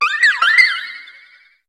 Cri de Guérilande dans Pokémon HOME.